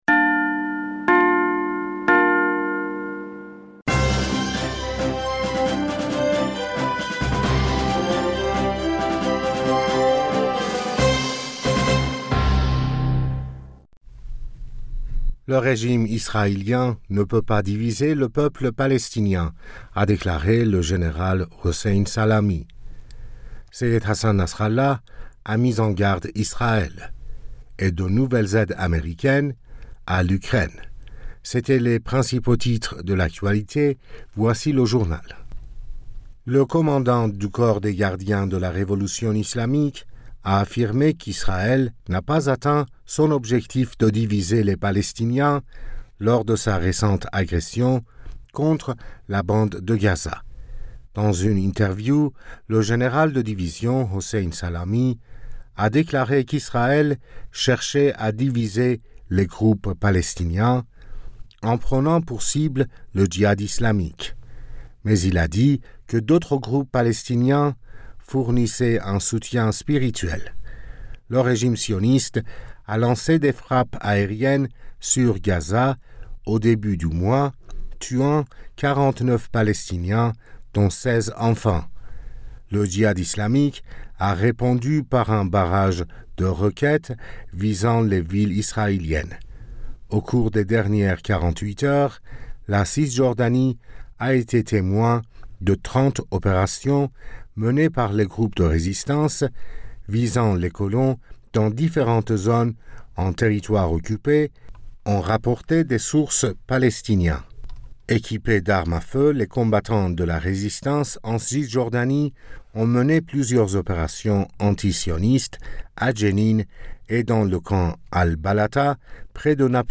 Bulletin d'information Du 20 Aoùt